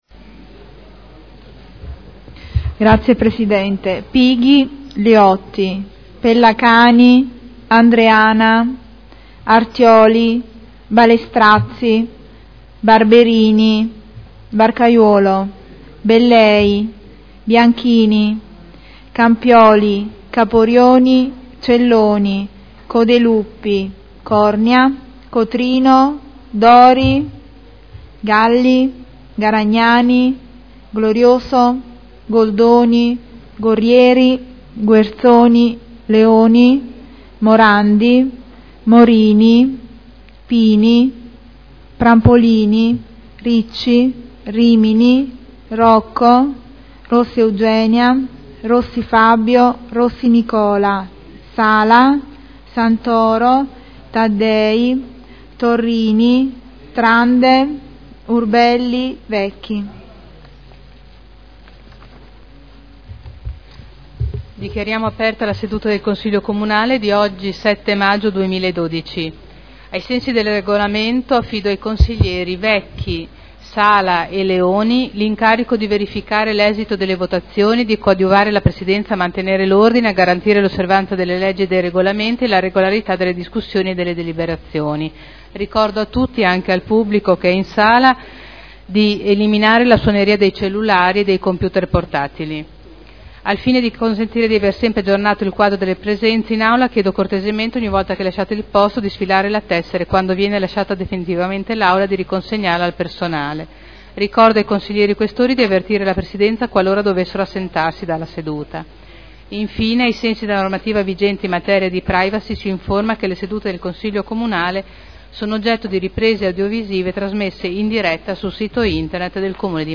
Appello del Segretario. La Presidente Caterina Liotti apre i lavori del Consiglio.